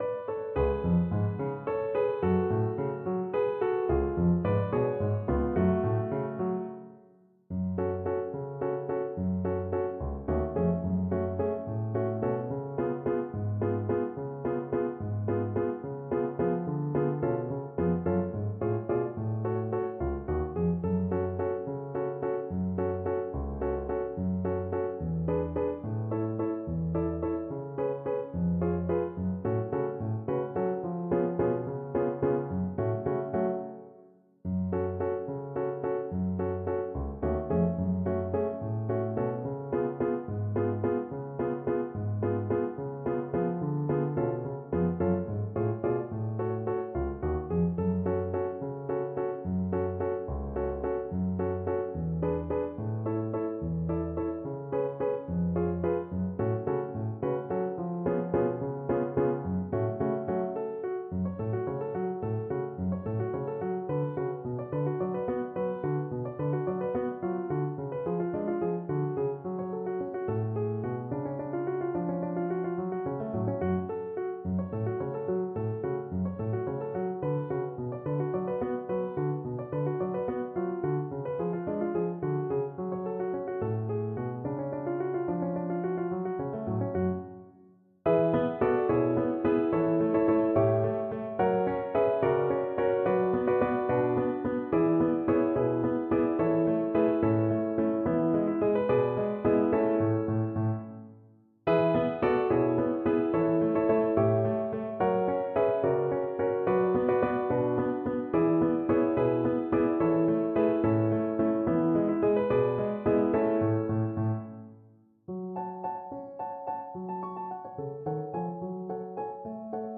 3/4 (View more 3/4 Music)
Moderate Waltz Tempo . = 72
Traditional (View more Traditional Violin Music)
Klezmer Violin